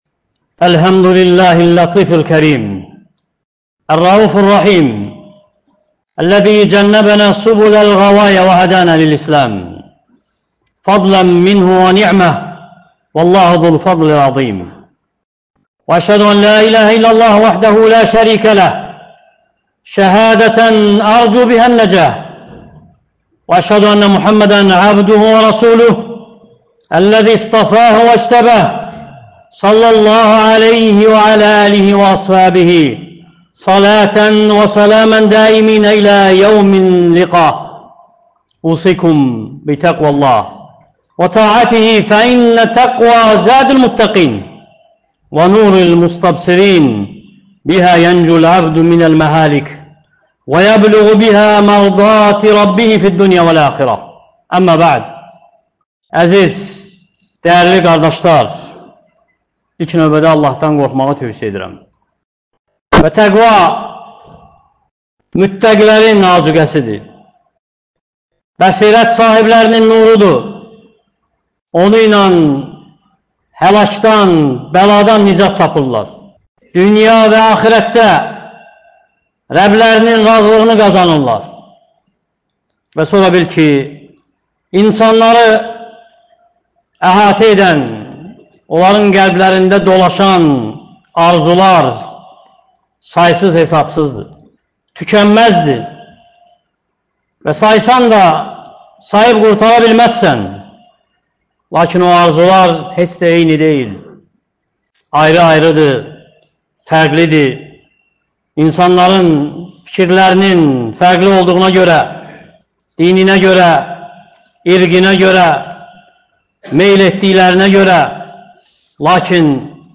Ölülərin arzuları (Cümə xütbəsi — 13.09.2024)